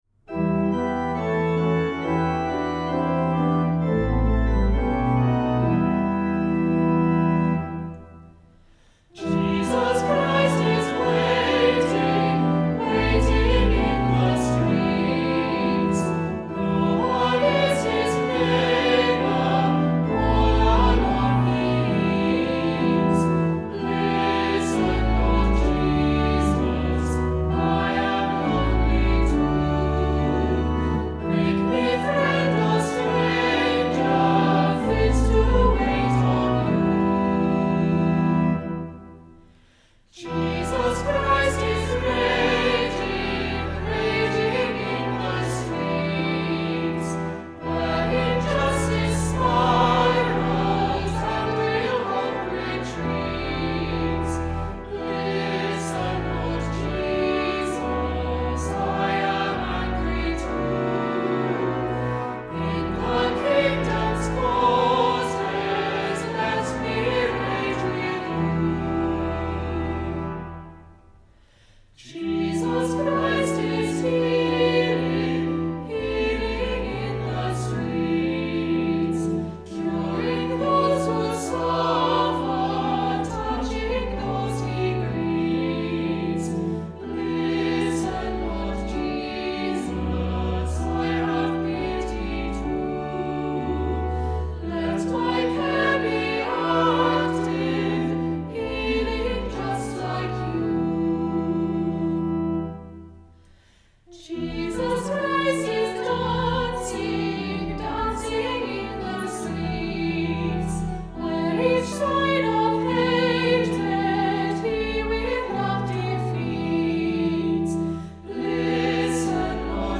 Hymns and songs were recorded remotely by the Choral Scholars of St Martin-in-the-fields in their homes, and edited together